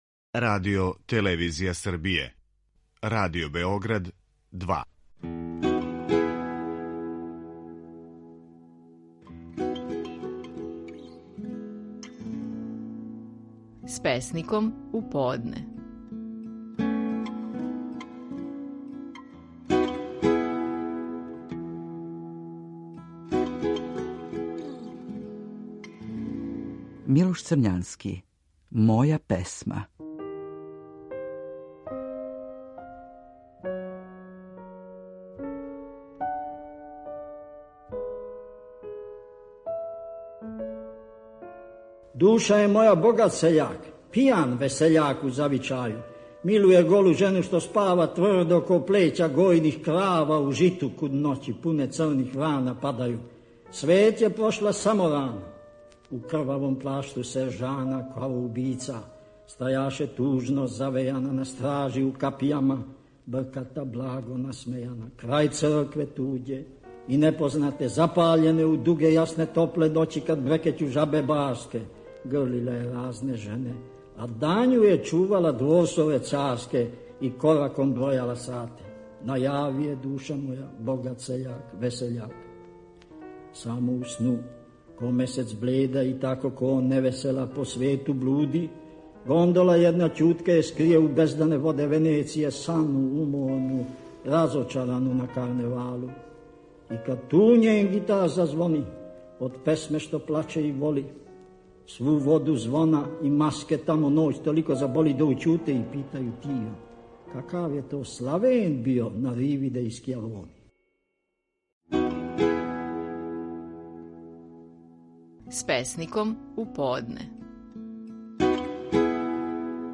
Стихови наших најпознатијих песника, у интерпретацији аутора.
„Моја песма" је наслов стихова које казује аутор Милош Црњански.